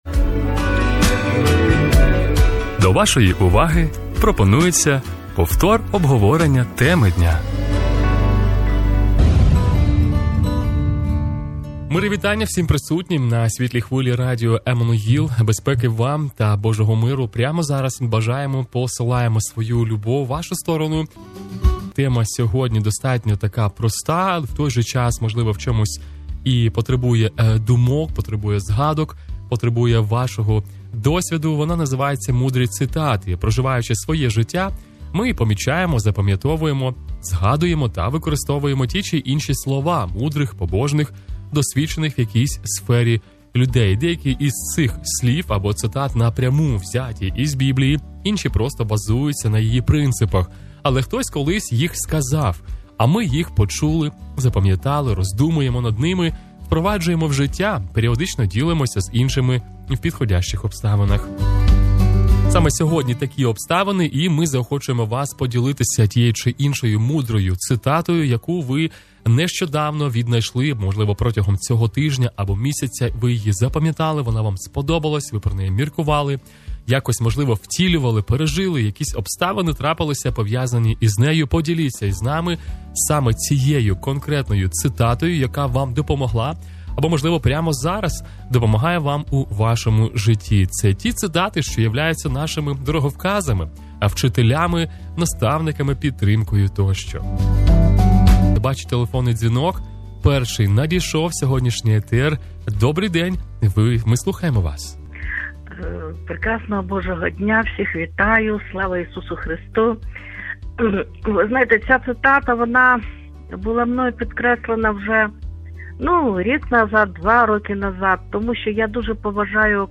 Ділимося мудрими цитатами в прямому етері радіо "Еммануїл"...